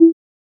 ProcessComplete.wav